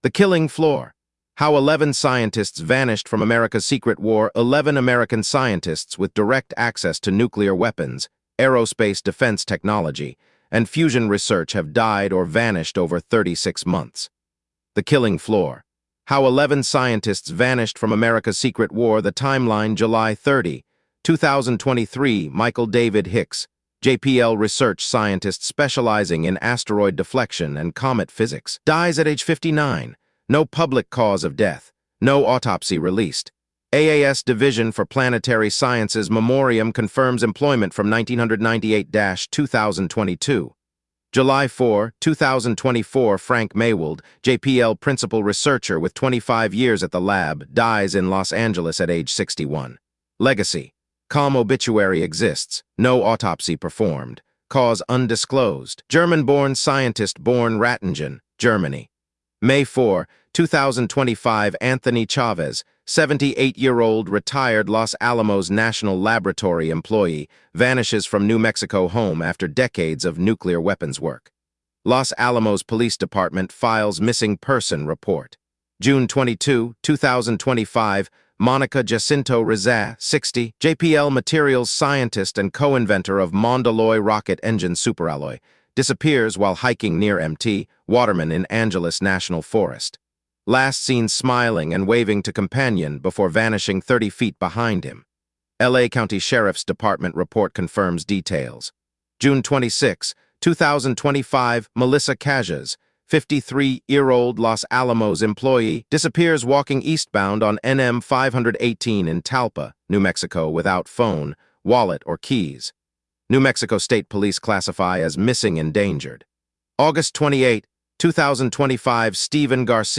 Read this article aloud